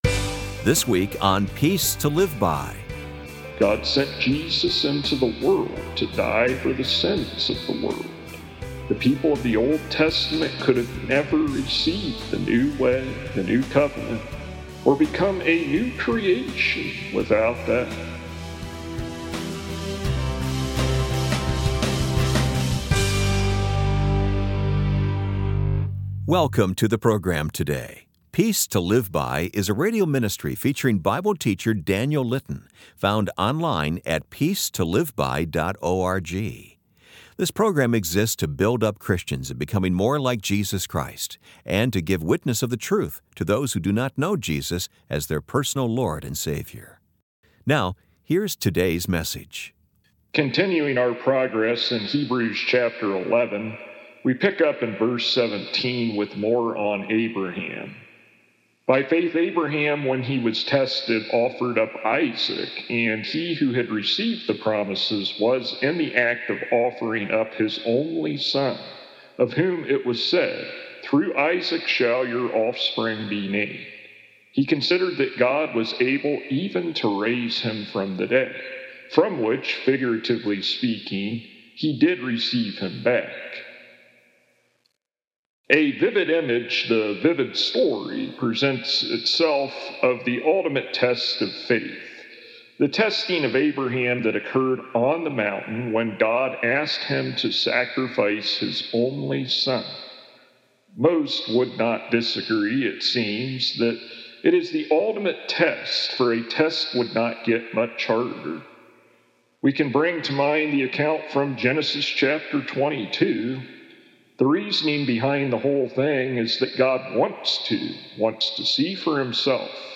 For full sermons without edits for time, tap here to go to downloads page. [Transcript represents full sermon's text] Continuing our progress in Hebrews chapter 11.